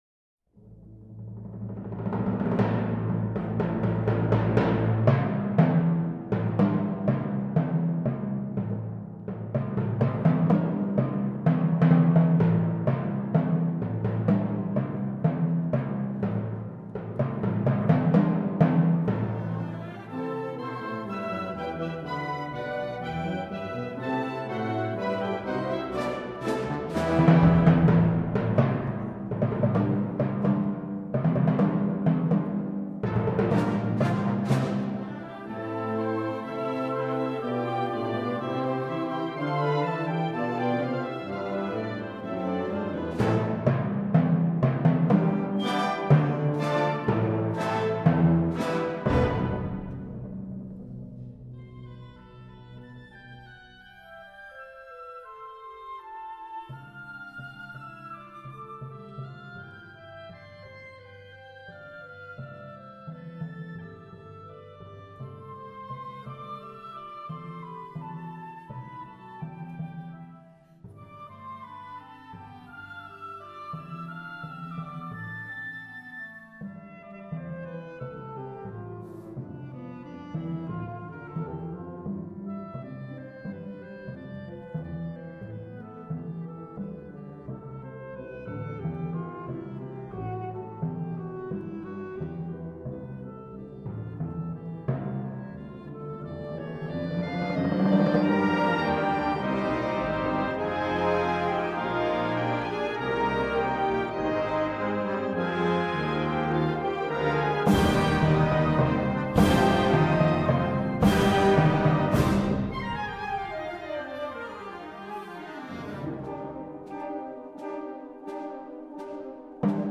Favorite Timpani/Percussion Repertoire